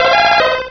Cri d'Osselait dans Pokémon Diamant et Perle.